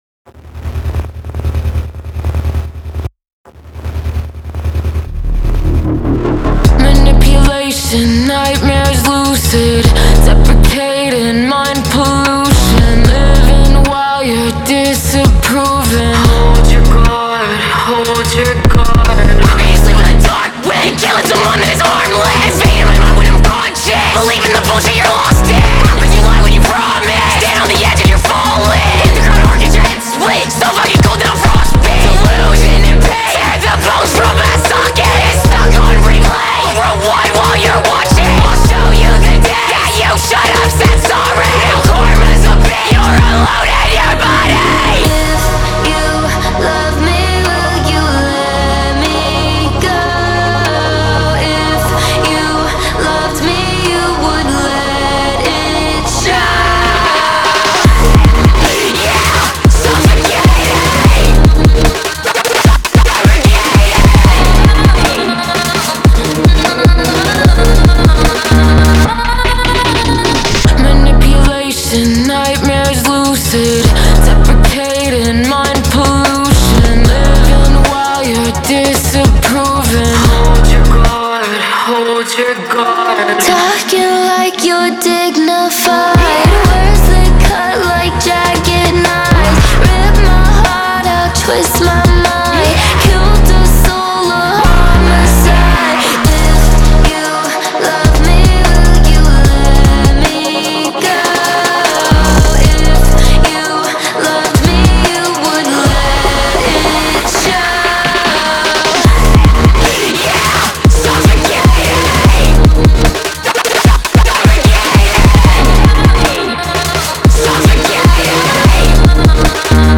• Жанр: Alternative